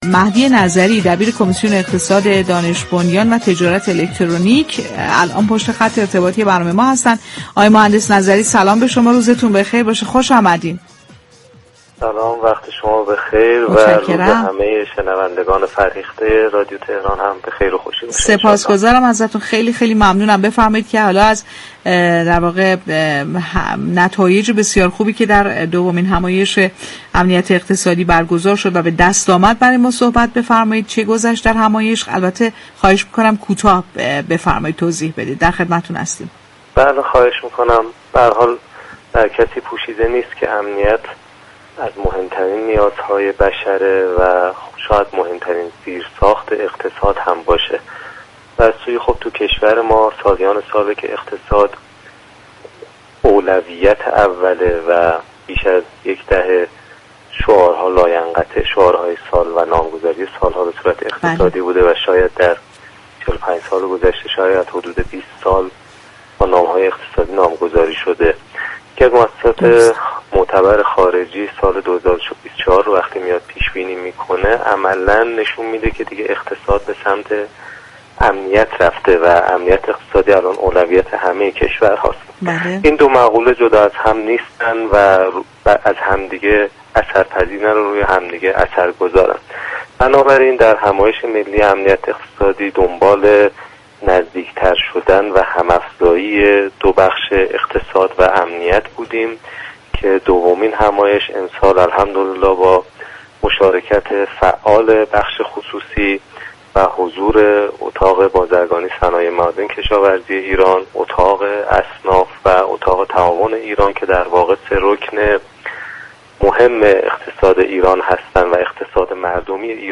گفت و گو